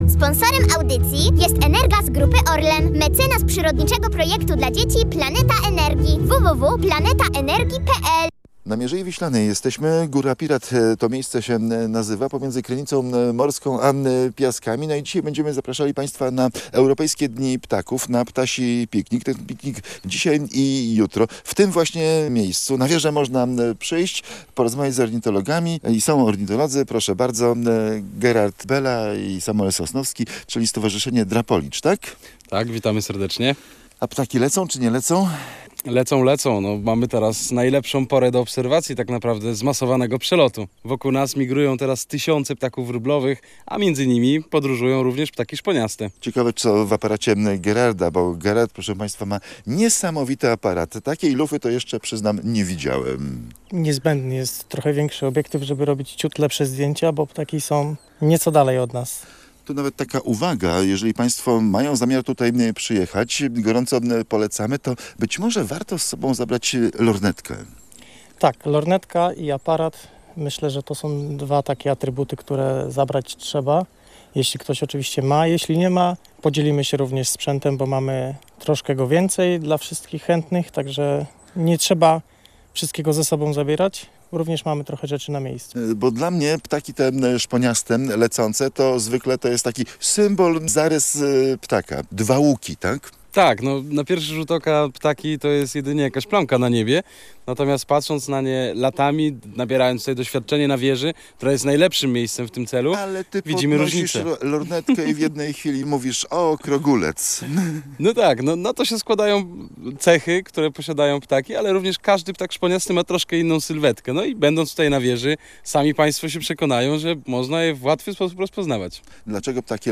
Ponownie jesteśmy na Mierzei Wiślanej. Migrujące ptaki potrafią tu lecieć grupami liczącymi setki tysięcy osobników. O fenomenie tego miejsca opowiadają ornitolodzy ze Stowarzyszenia Drapolicz.